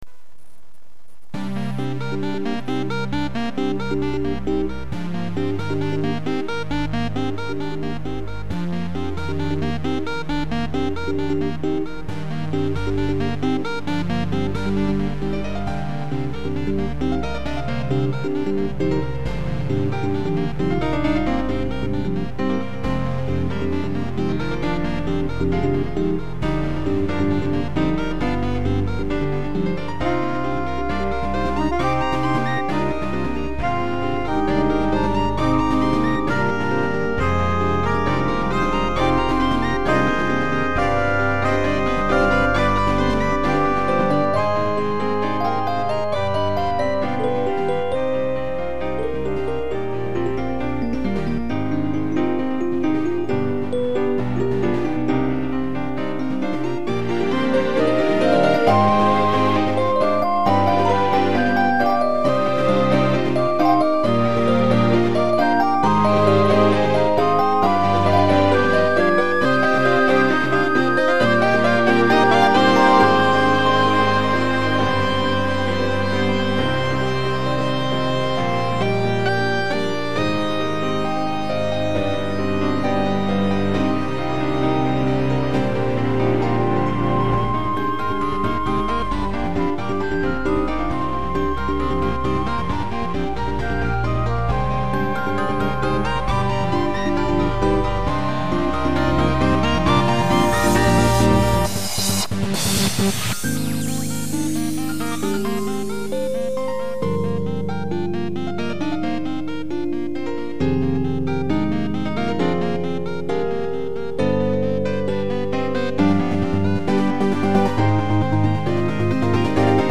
普段あまり使わないシンセウェーブなどを多用し、違う世界観の演出、 それでいて感情的な表現は損なわないように。